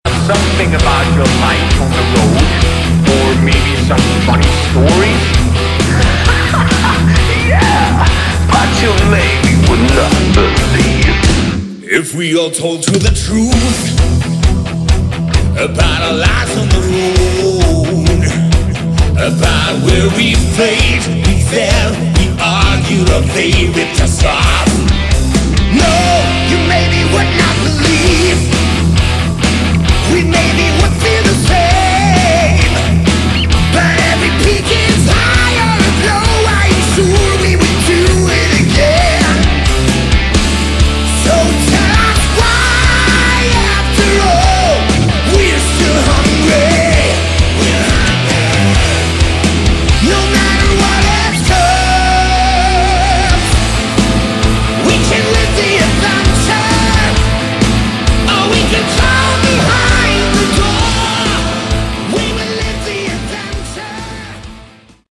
Category: Melodic Metal
vocals
lead guitars
rhythm guitars
bass
drums